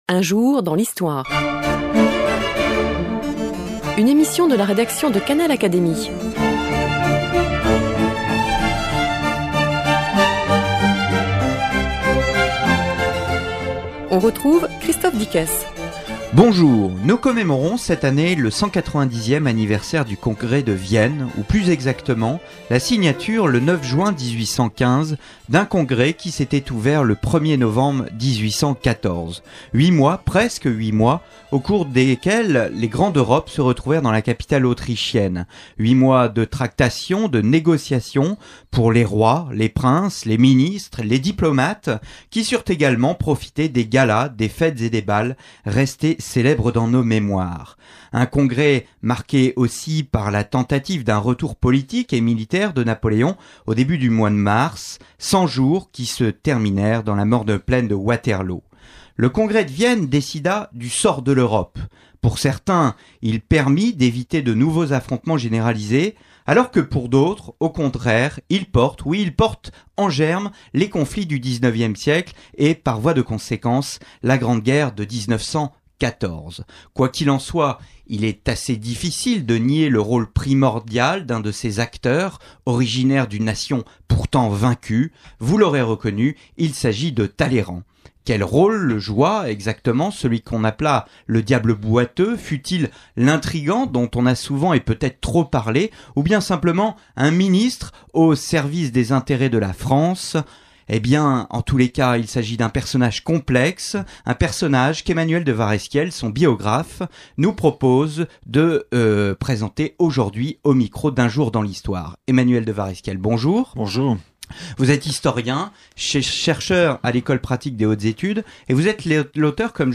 reçoit l’historien Emmanuel de Waresquiel, biographe de Talleyrand.